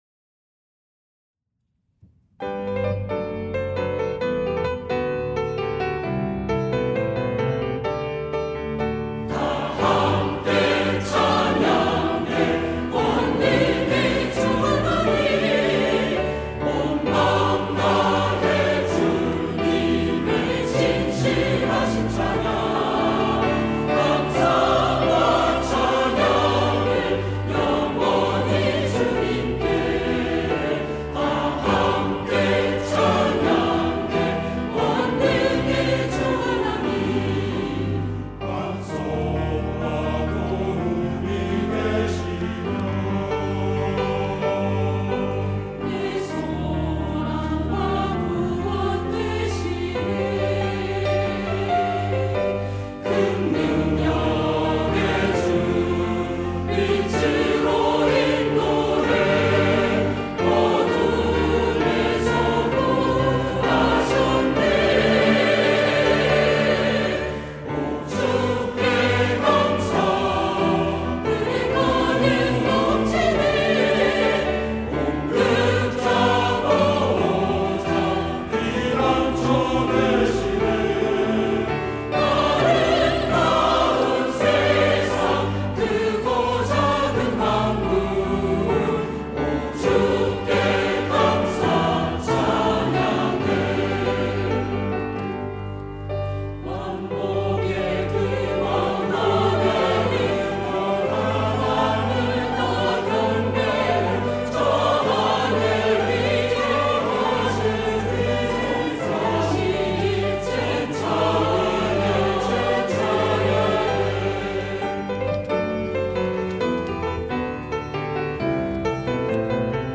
시온(주일1부) - 다함께 찬양해
찬양대